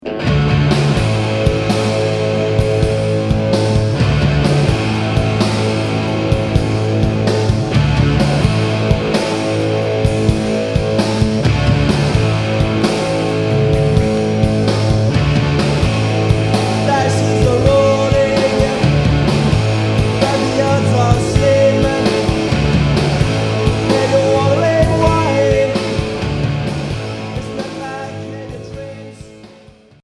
Rock punk Unique 45t